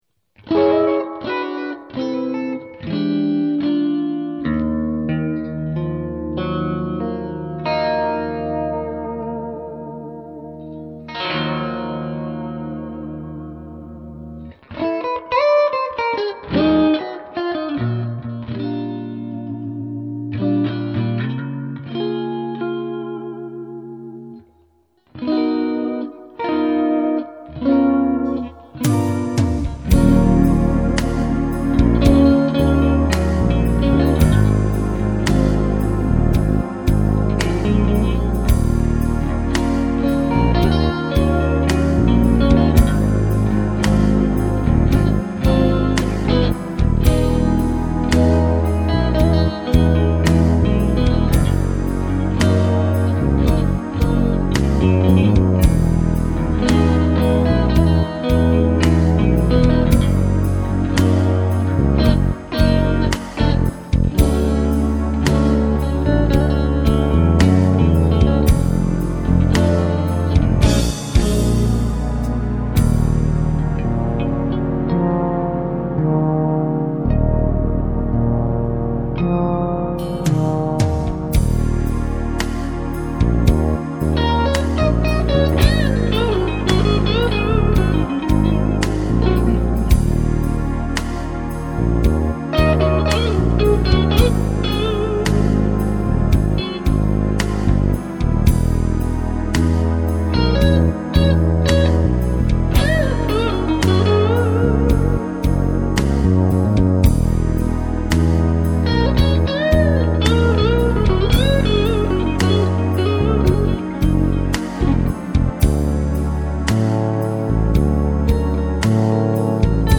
予想してた以上の音が出た．ややトレブリーだが，透き通るような高音が出て，太すぎず細すぎず，いい感じだ．
・Guitar：Relicよ〜ん
・Amp：PODを使ってPCで録音しました
・伴奏：MIDIで打ち込み（音源はSC88Pro）   2分28秒（2.27MB）　MP3：128bps/ステレオ